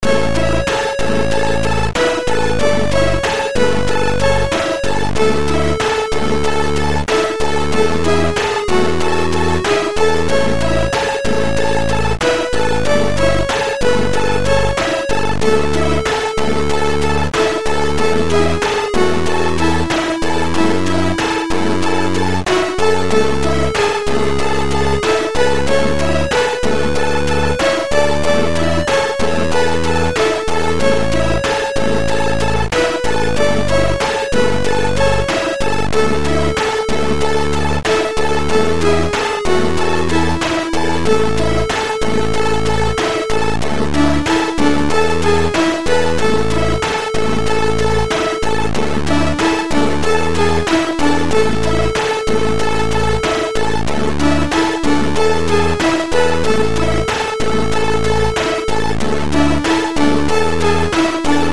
chip